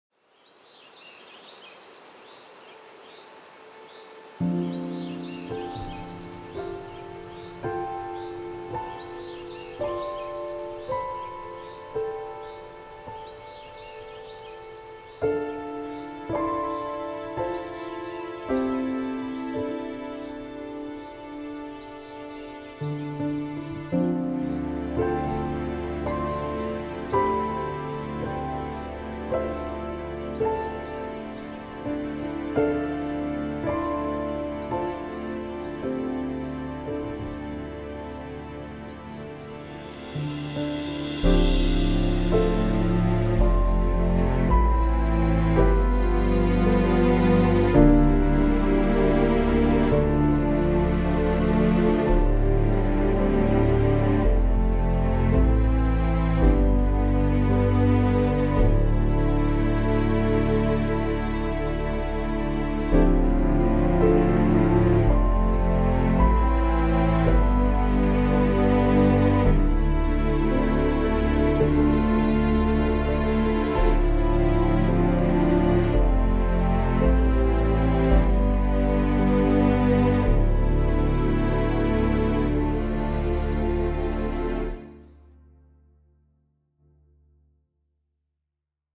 Moderne Filmmusik